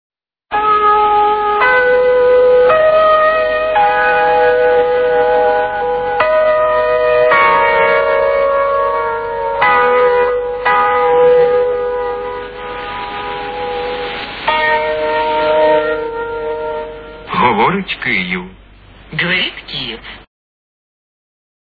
позивні від 1978 р.